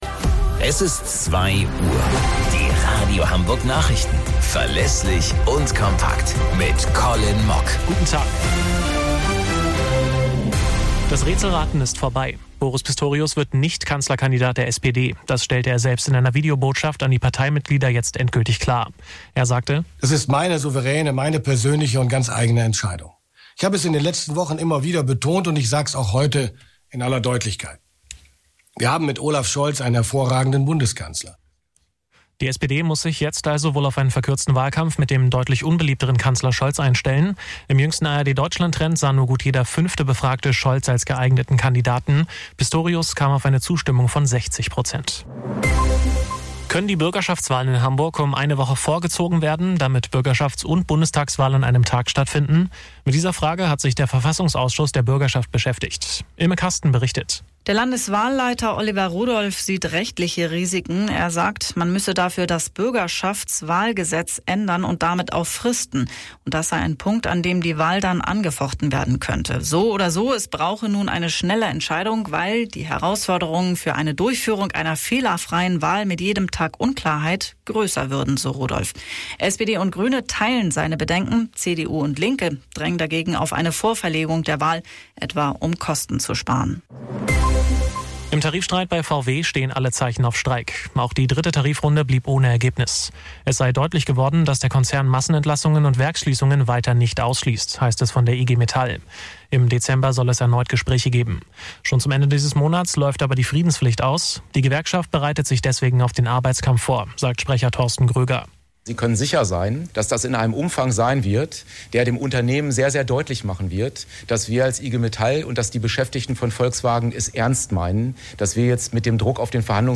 Radio Hamburg Nachrichten vom 21.01.2025 um 17 Uhr - 21.01.2025